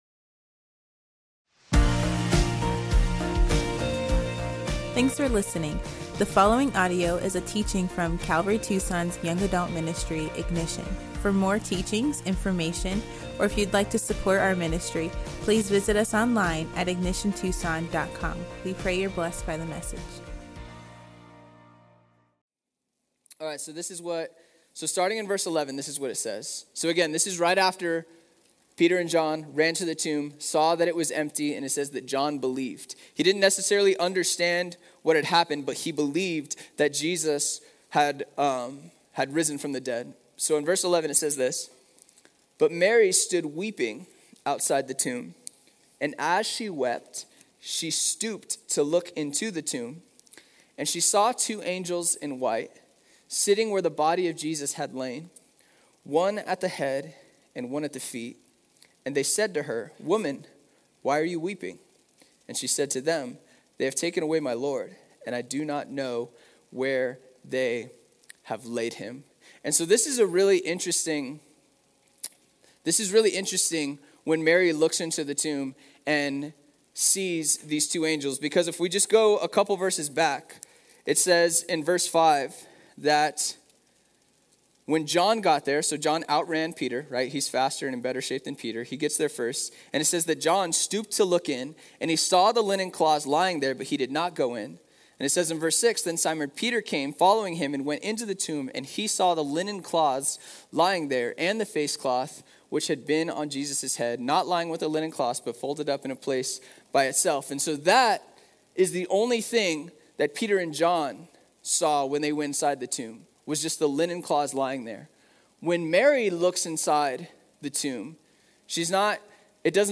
Young Adults